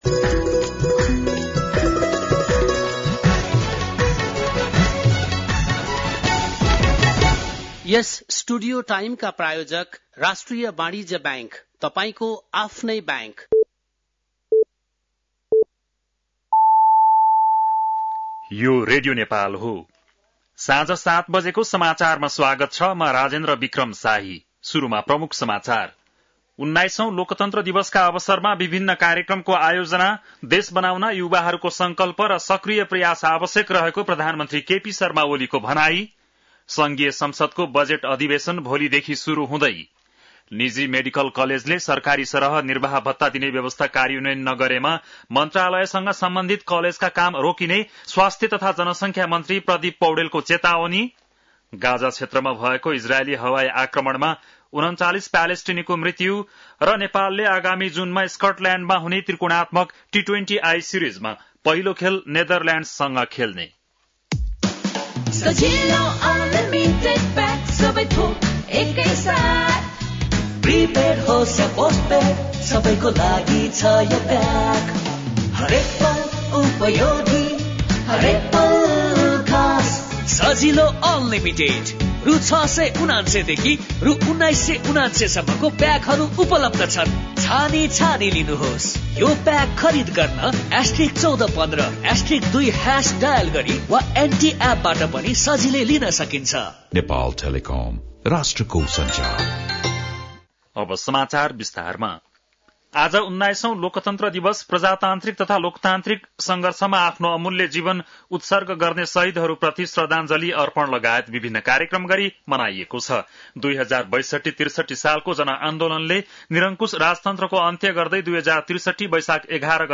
बेलुकी ७ बजेको नेपाली समाचार : ११ वैशाख , २०८२